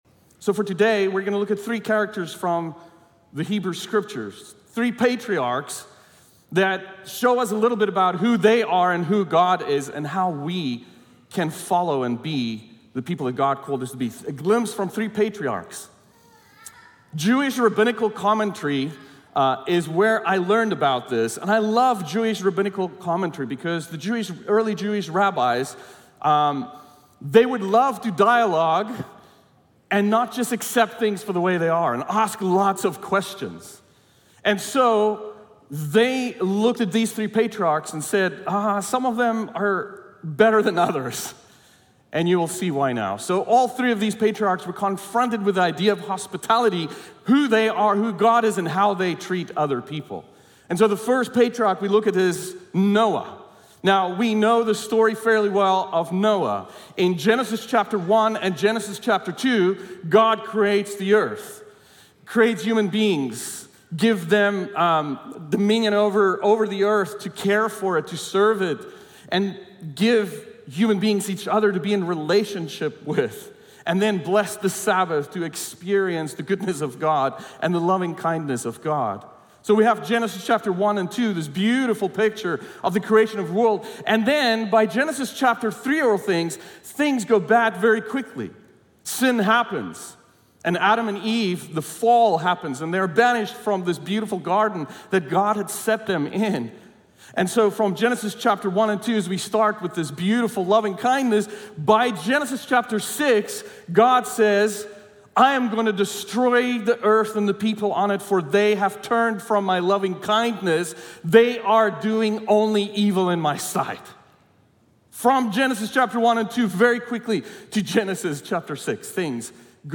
Sermon Series Archive | La Sierra University Church